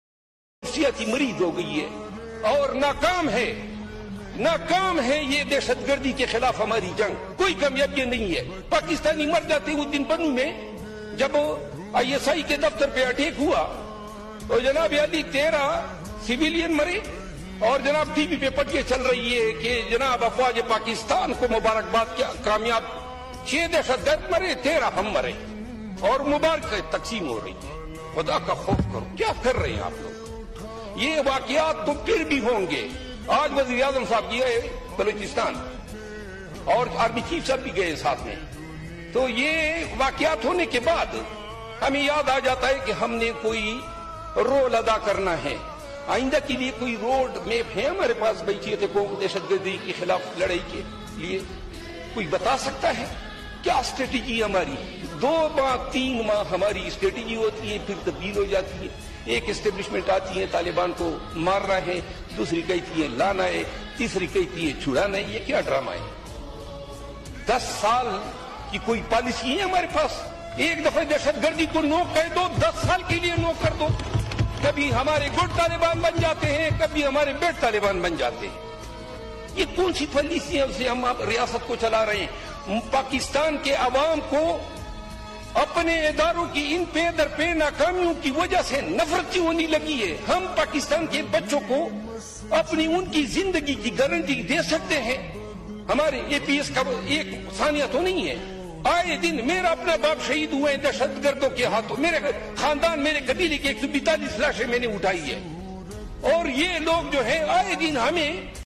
Best Speech Of The Year.....